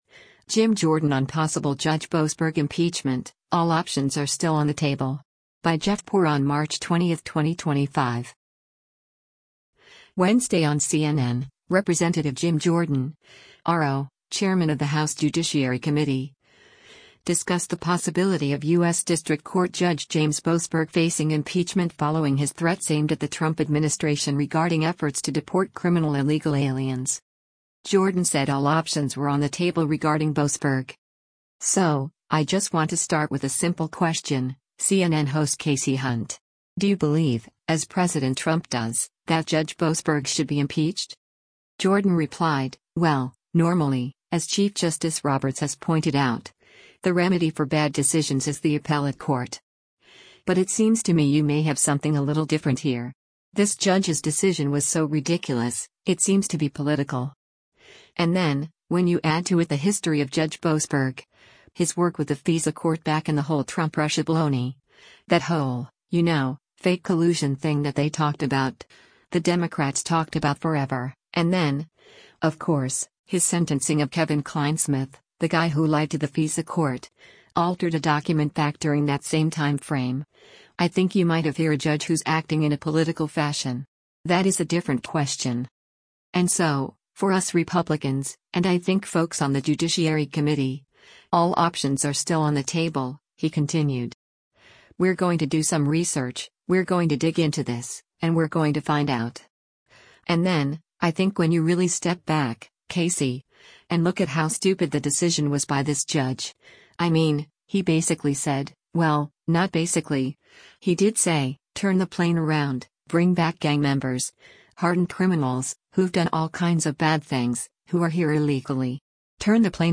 Wednesday on CNN, Rep. Jim Jordan (R-OH), chairman of the House Judiciary Committee, discussed the possibility of U.S. District Court Judge James Boasberg facing impeachment following his threats aimed at the Trump administration regarding efforts to deport criminal illegal aliens.